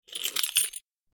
جلوه های صوتی
دانلود صدای ماشین 20 از ساعد نیوز با لینک مستقیم و کیفیت بالا